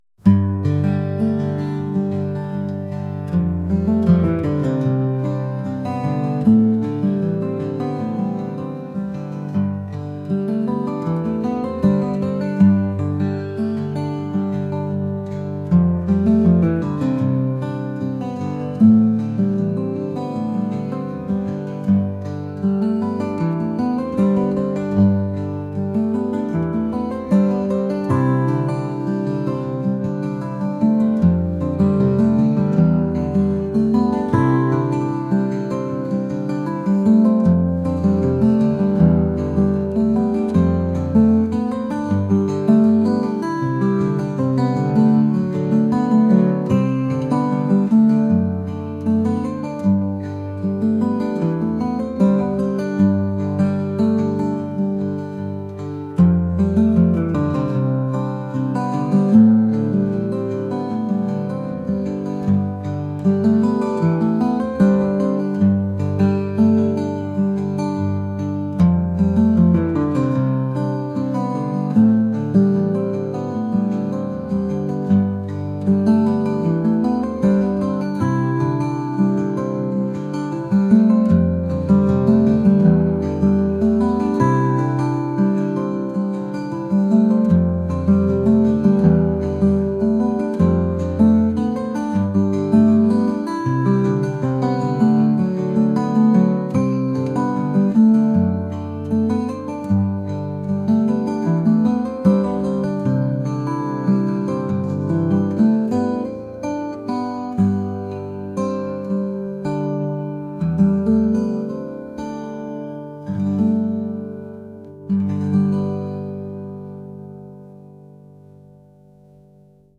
folk | acoustic | ambient